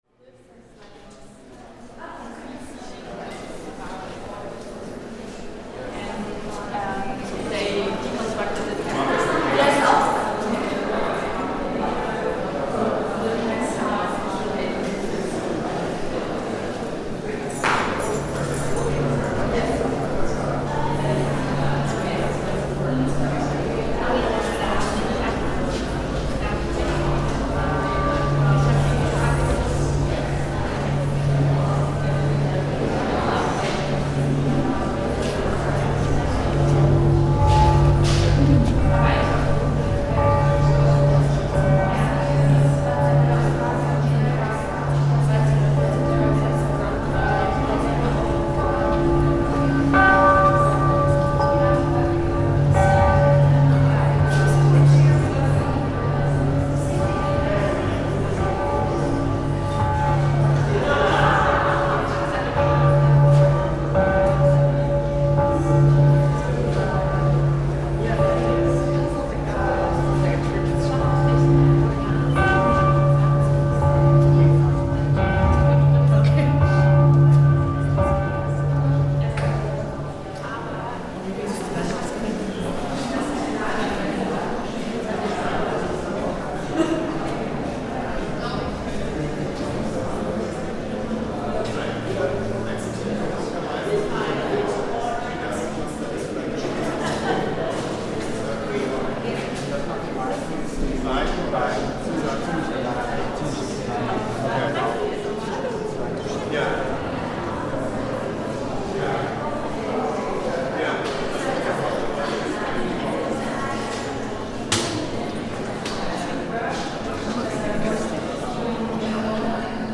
The space has a fascinating past as a shelter, a prison for political prisoners, and a techno and sex club - and now it hosts a conceptual art exhibition. The sound captures both the muffled sounds of museum-goers on one side, and the passage of air and the sounds from Berlin outside on the other. Recorded in Berlin by Cities and Memory, September 2025.